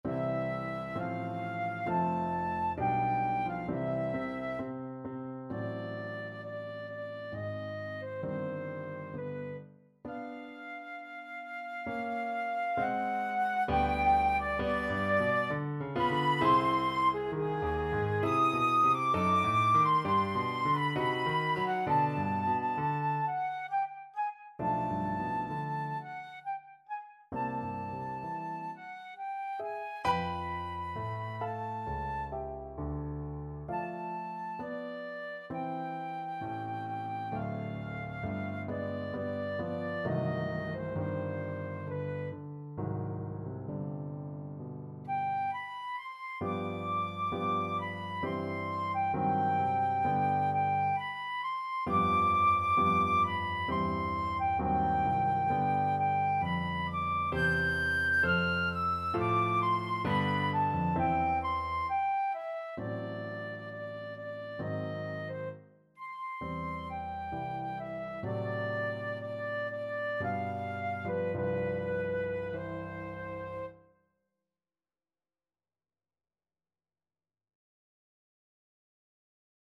Andante Sostenuto, Main Theme Flute version
Flute
C major (Sounding Pitch) (View more C major Music for Flute )
3/4 (View more 3/4 Music)
=66 Andante sostenuto
Classical (View more Classical Flute Music)